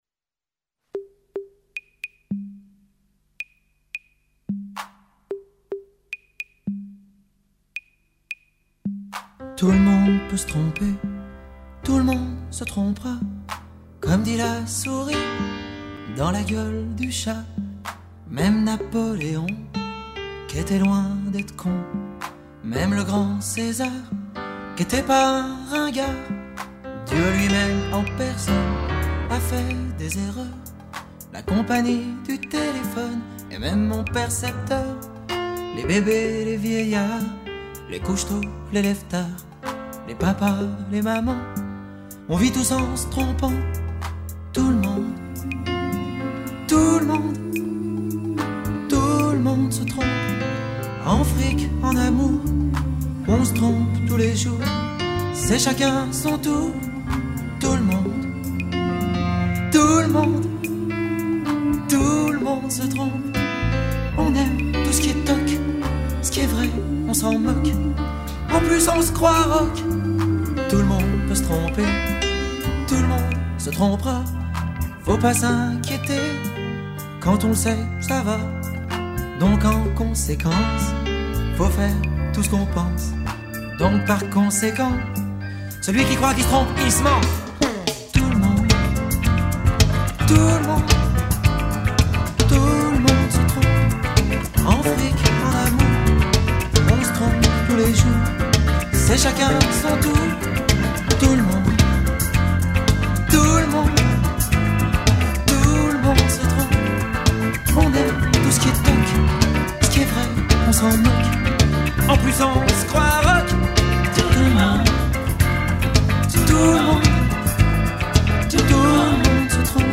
风格流派：法语香颂